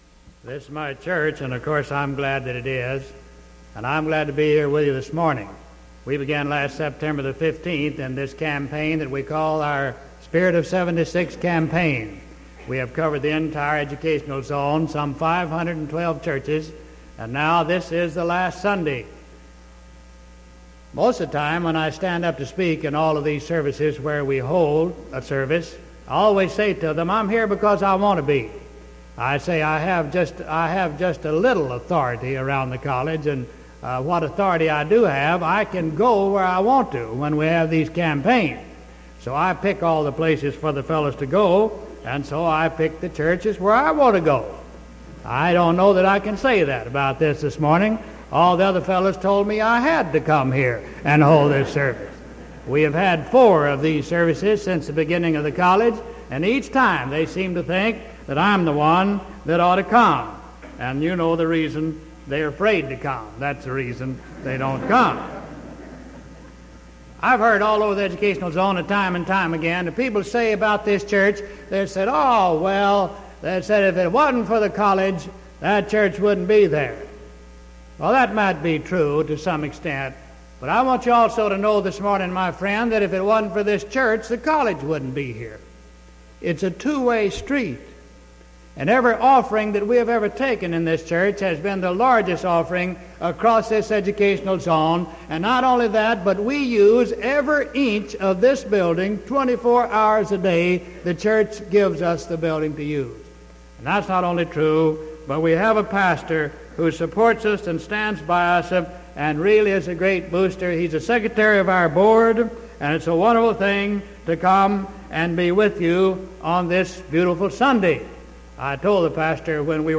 Sermon January 19th 1975 AM